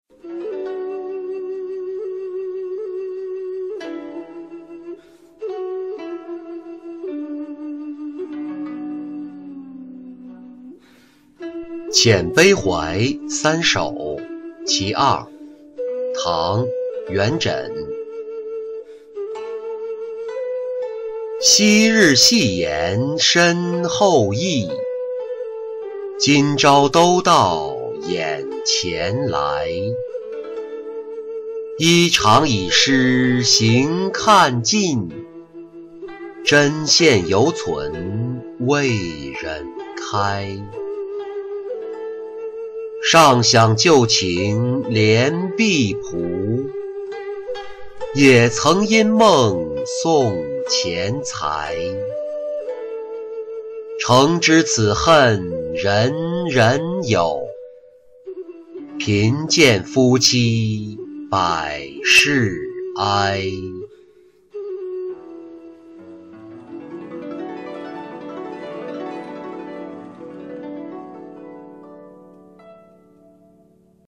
遣悲怀三首·其二-音频朗读